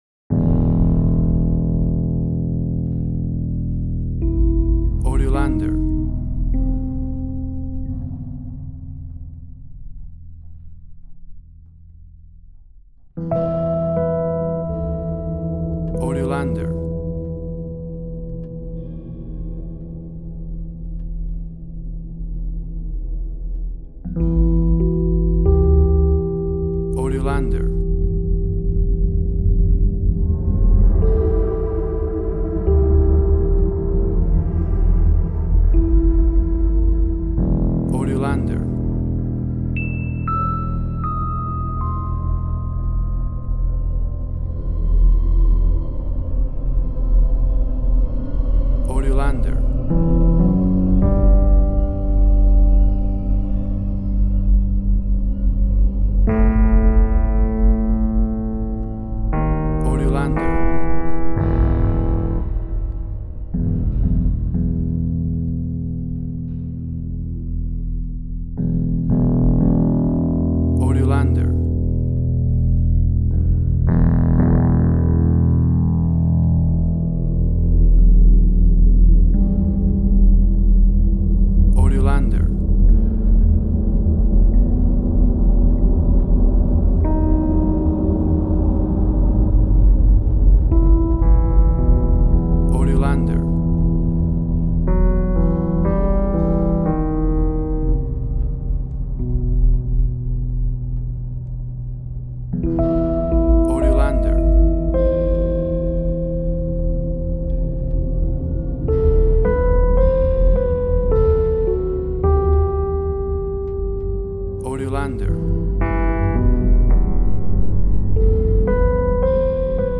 WAV Sample Rate: 24-Bit stereo, 44.1 kHz